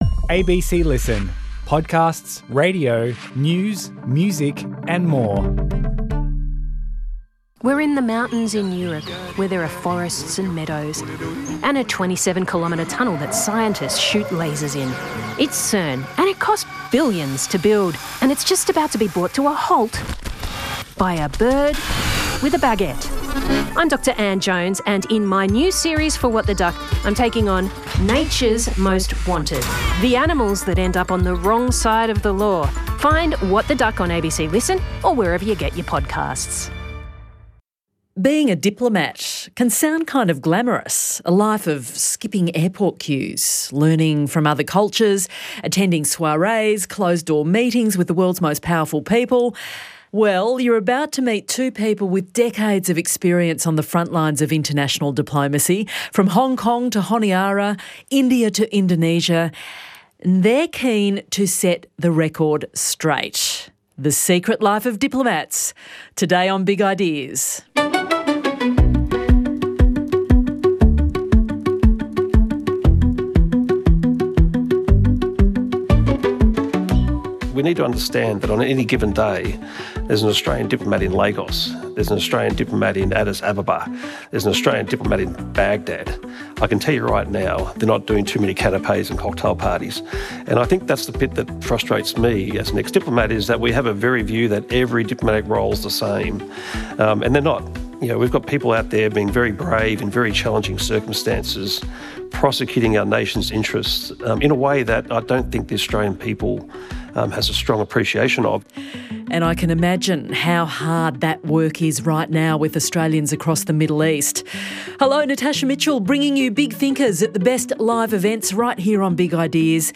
This event was recorded at the 2025 Canberra Writers Festival.